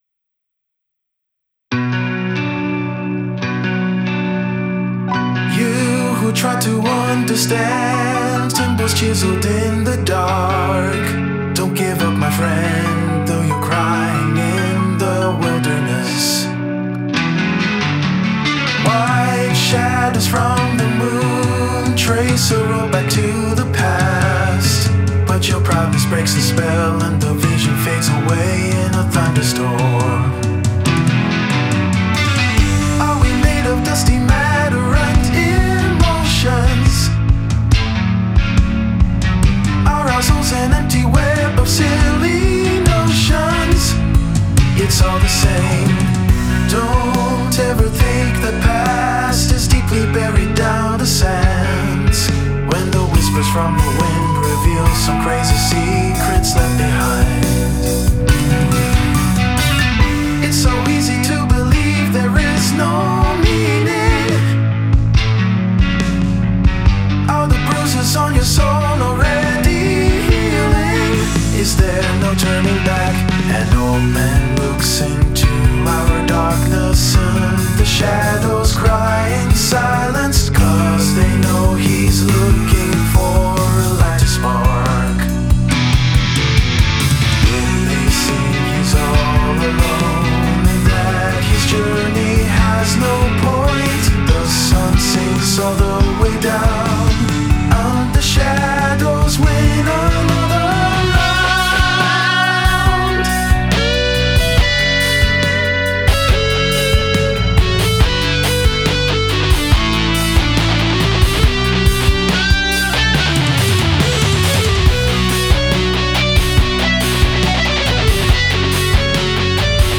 Al tener la posibilidad de grabarla con voces, cambié la letra a inglés.
Bajándola a 140, conseguí que sonase mejor.
Después la oí con más calma, y le he añadido mejores armonías y mejor mezcla y masterización.